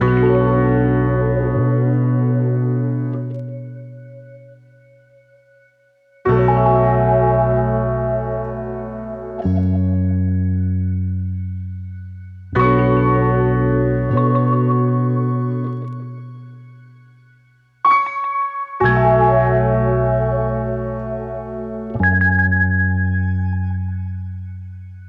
I also used the GForce Bass Station 2 for the synth chord and the bass sound.